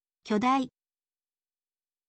kyodai